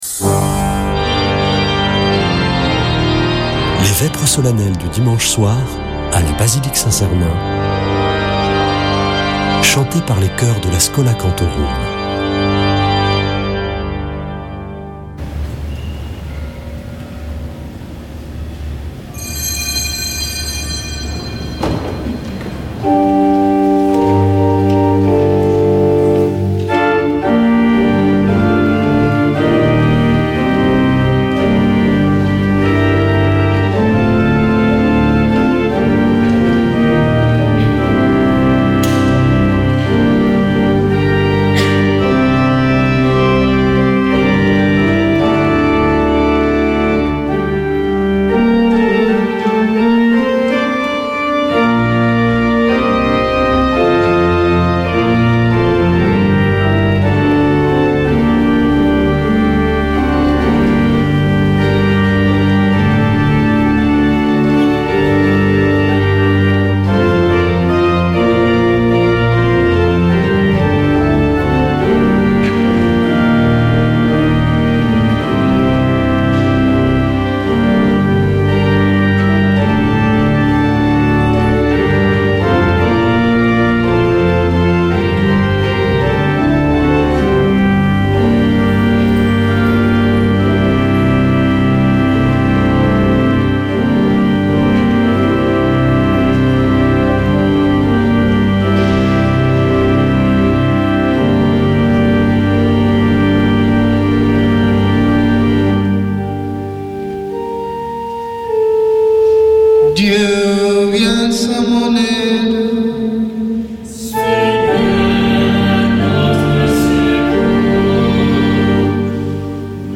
Vêpres de Saint Sernin du 15 oct.
Une émission présentée par Schola Saint Sernin Chanteurs